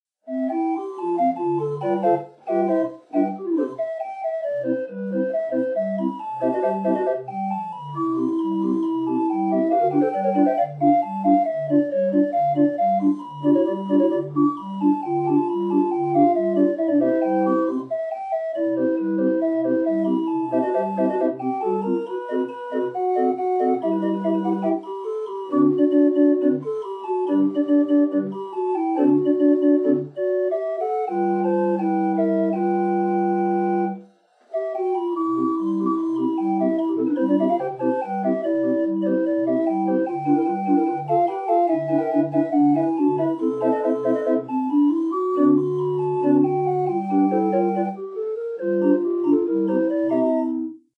Les premiers sons dignes d'�tre enregistr�s.